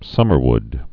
(sŭmər-wd)